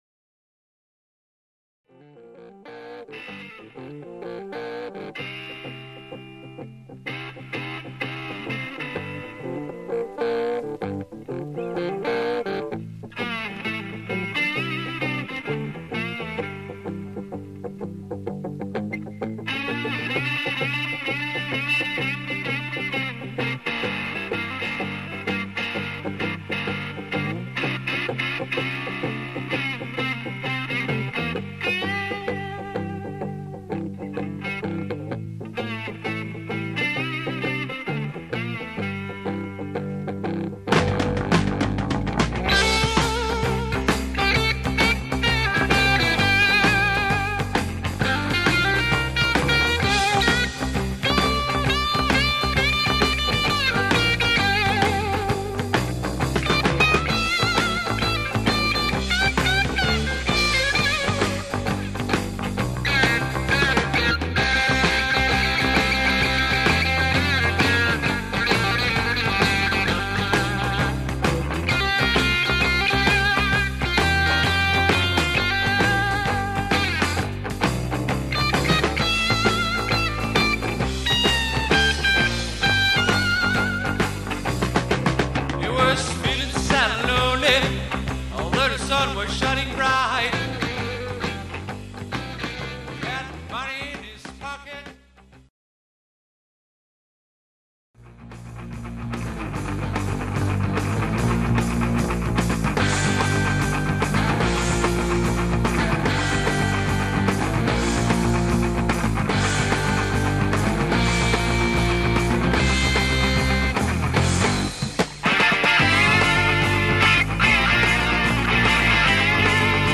Progressive / Symphonic